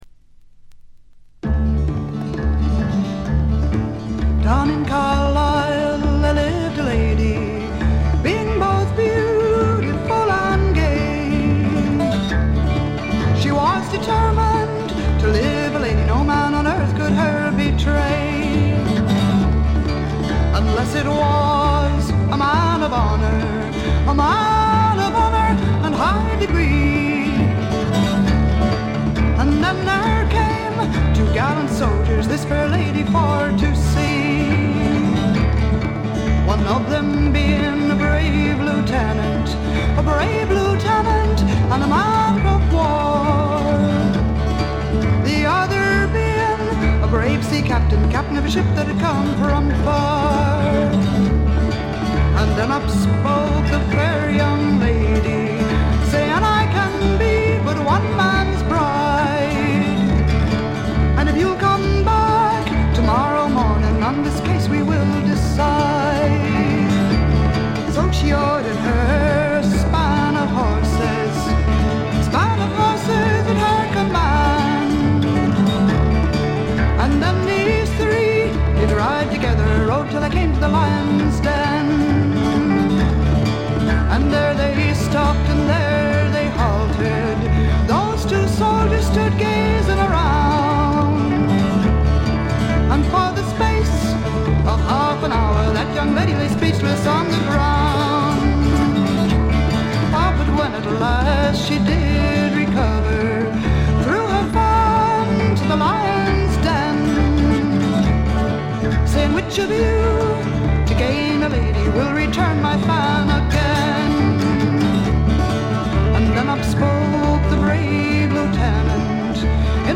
実に魅力的なアルト・ヴォイスの持ち主で、初めて聴く方はまずはこの声にやられてしまうことでしょう。
この強力な声を武器にシンプルなバックを従えて、フォーキーでジャジーでアシッドでダークなフォークロックを展開しています。
試聴曲は現品からの取り込み音源です。